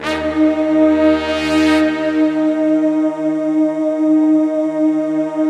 Index of /90_sSampleCDs/Optical Media International - Sonic Images Library/SI1_BrassChoir/SI1_SlowChoir
SI1 BRASS05R.wav